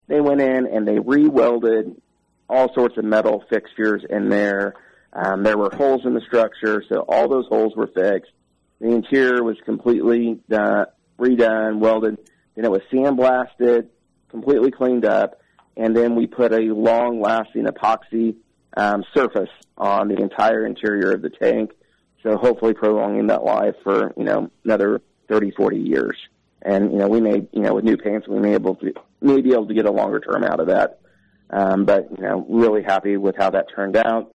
City Manager Trey Cocking gave a full rundown of the interior work during an appearance on KVOE’s Morning Show Wednesday.